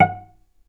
vc_pz-F#5-mf.AIF